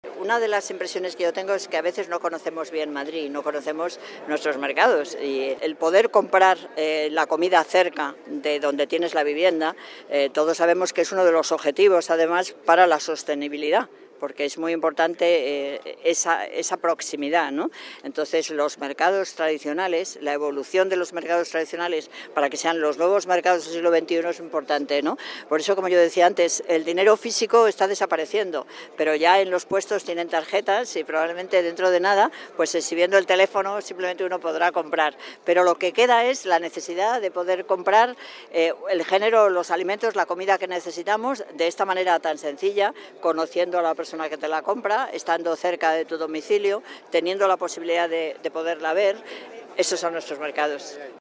Nueva ventana:Declaraciones de Manuela Carmena en el mercado de Ventas